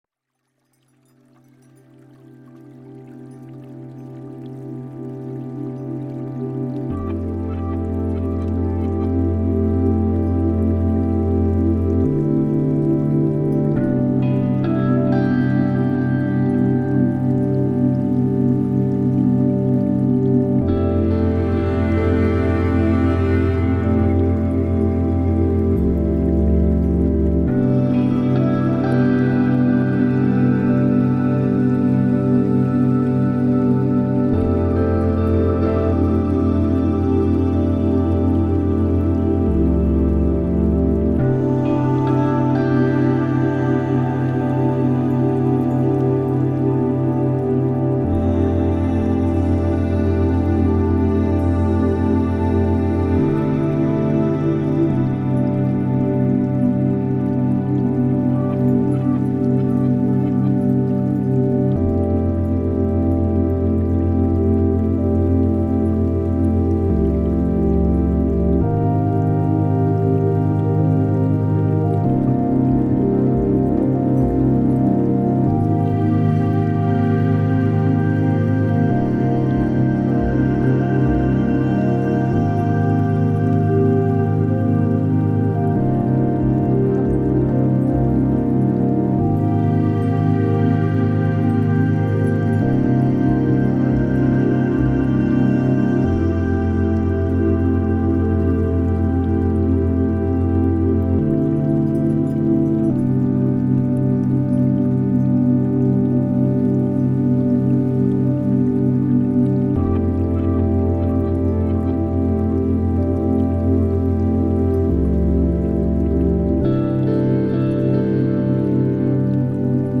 CHANTS VIBRATOIRES
Meditation-regenerer-la-croissance-cellulaire.mp3